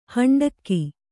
♪ haṇḍakki